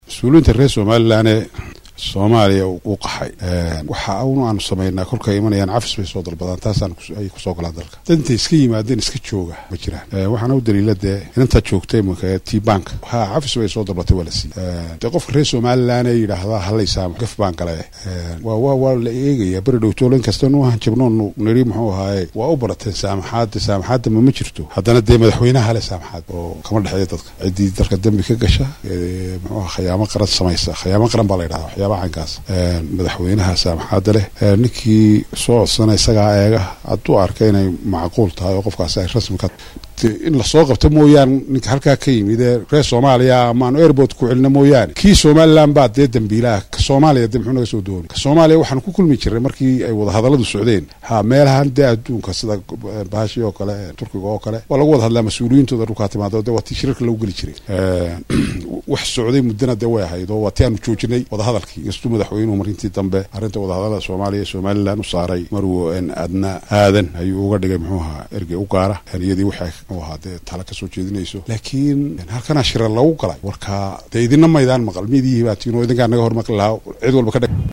Wasiiru dawlaha Wasaaradda Arrimaha Gudaha Somaaliland Maxamed Muuse Diiriye, oo Warbaahinta kula hadlayay Magaalada hargeysa ayaa beeniyay warar sheegayay in Hargeysa ay tageen siyaasiyiin Soomaliyeed,waxa uuna sheegay in hadii ay tagaan in dib loogu soo celin doono.